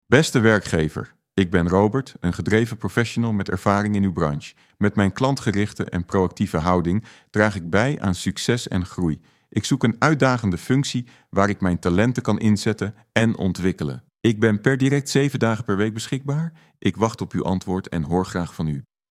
Voice AI Agents »
Robert-intro.mp3